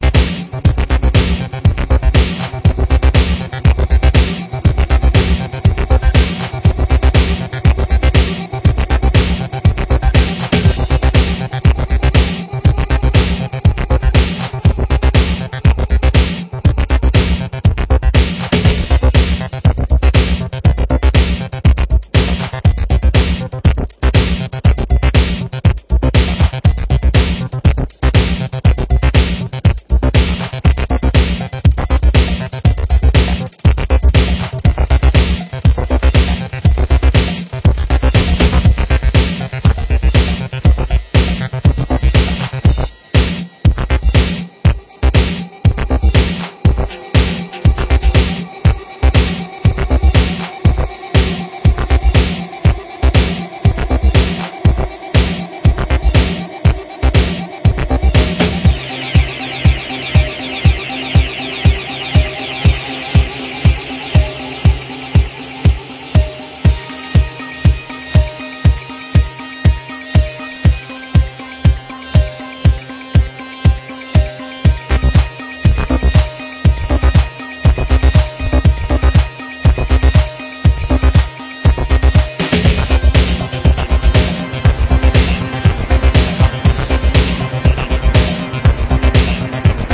Pornotrance from outta space!